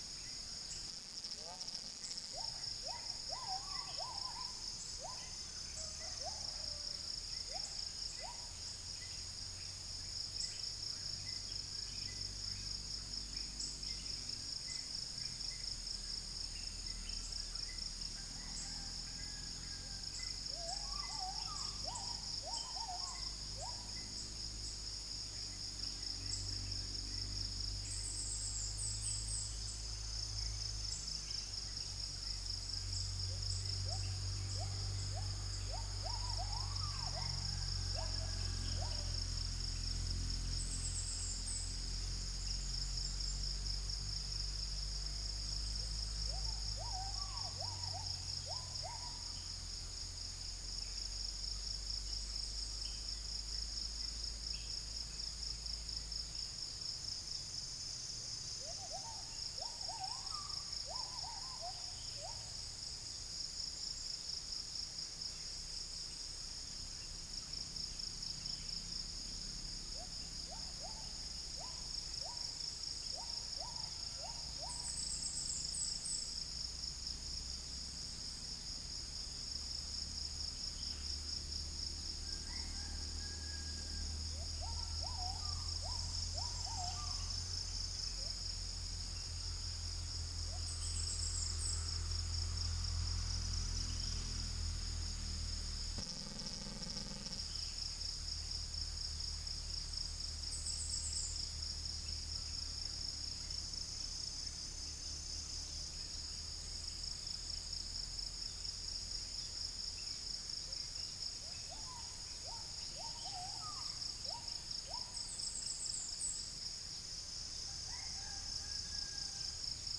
Upland plots dry season 2013
Gallus gallus domesticus
Spilopelia chinensis
Orthotomus sericeus
Todiramphus chloris
Chloropsis moluccensis